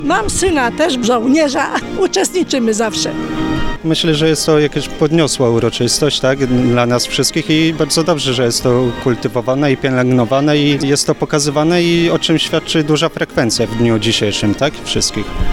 Na Placu Staromiejskim tłumnie zebrali się mieszkańcy Stargardu, aby zobaczyć wystawę wozów wojskowych, defiladę, czy salwę honorową.